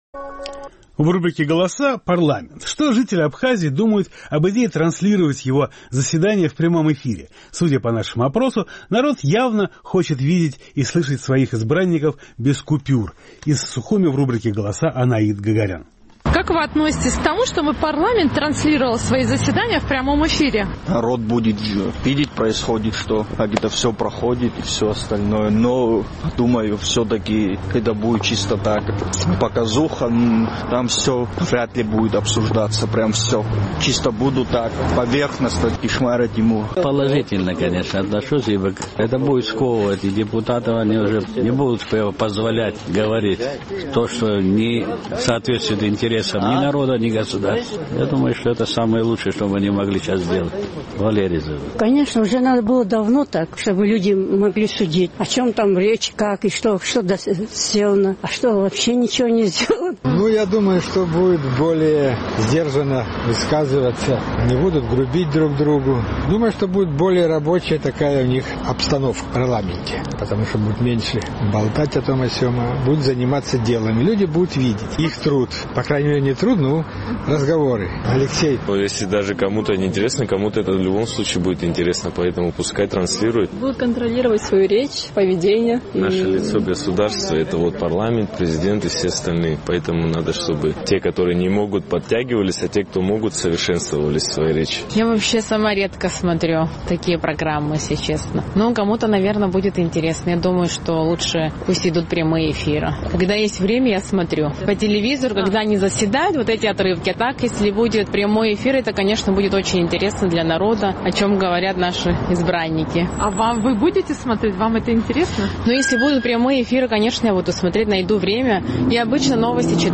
Наш сухумский корреспондент поинтересовалась у местных жителей, как они относятся к тому, чтобы парламент транслировал свои заседания в прямом эфире.